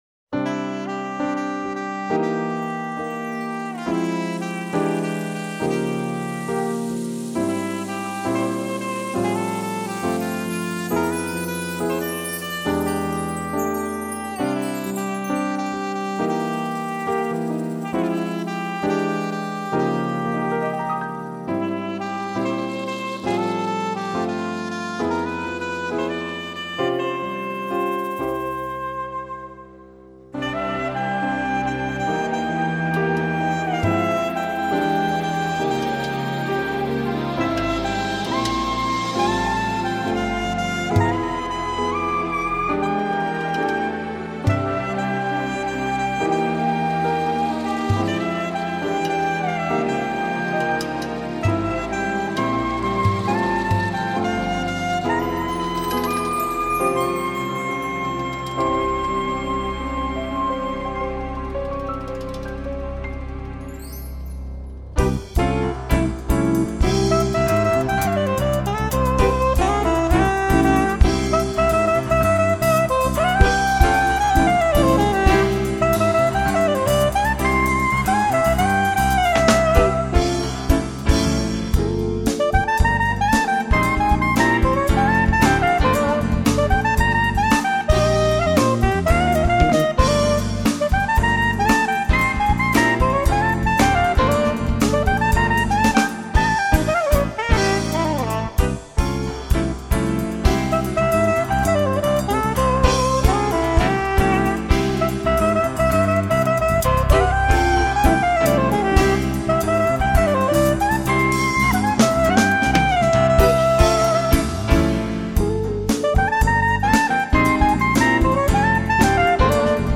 1334   06:04:00   Faixa:     Jazz
Sax Soprano
Piano Elétrico
Guitarra
Bateria
Percussão
Baixo Elétrico 6